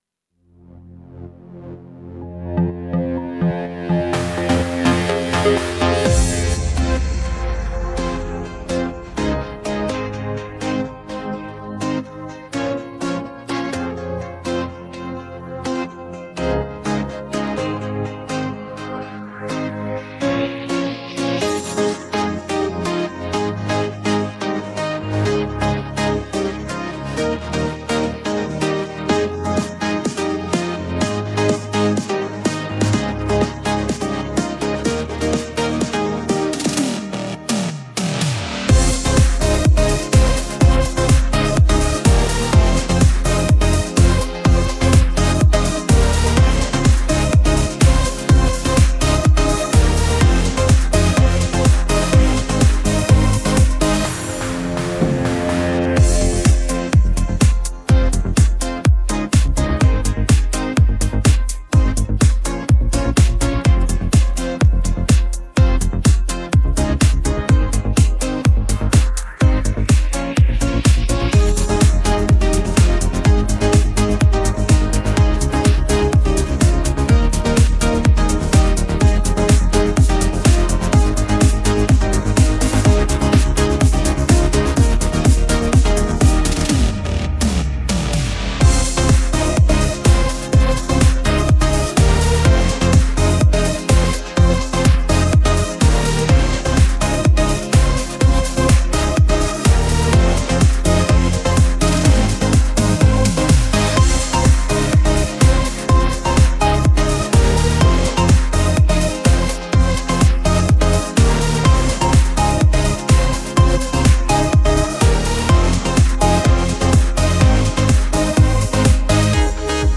• Категория: Детские песни
Слушать минус
минусовка